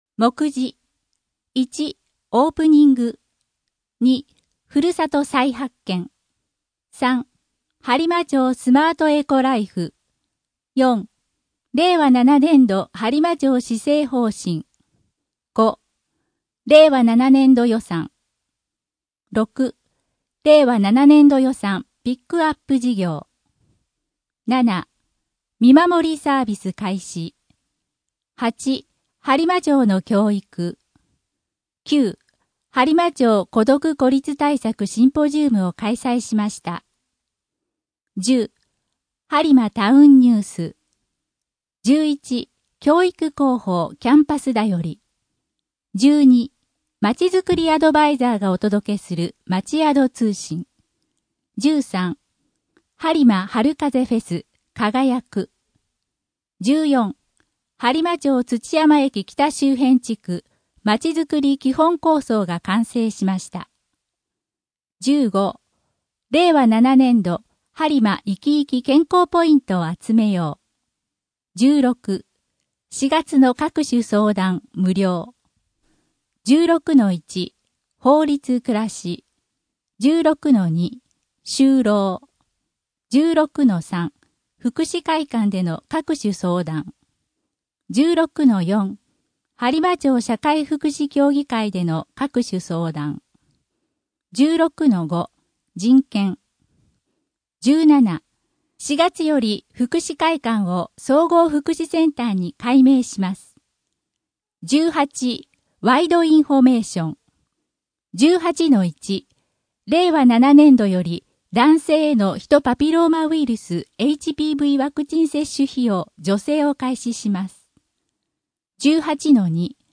声の「広報はりま」4月号
声の「広報はりま」はボランティアグループ「のぎく」のご協力により作成されています。